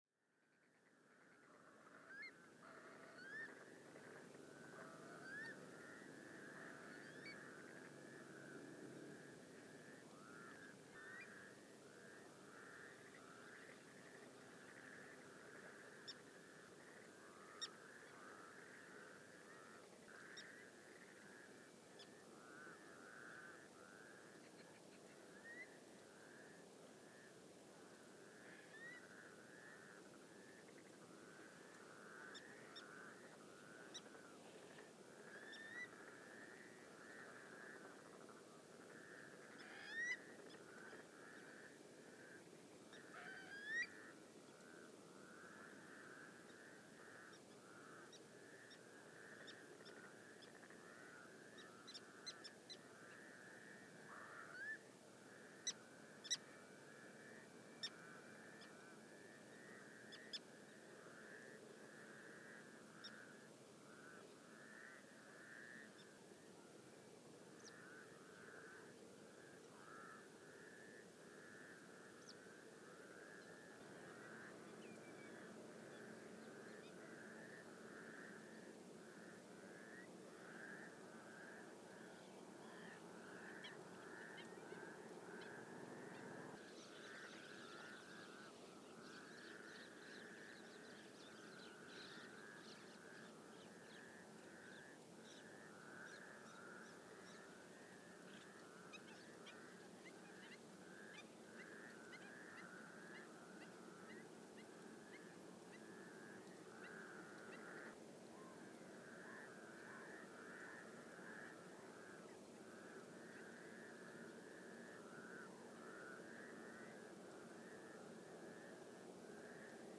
The sound of a Beijing wetland at dusk
A few days ago I was lucky to spend the last couple of hours of daylight on the edge of one of Beijing’s primary wetlands in Yanqing District. With the wind slowly dying as the sun set, the sounds came into their own. I set up my digital sound recorder and just sat back and relaxed.